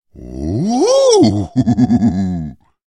7. Эврика, мужчина догадался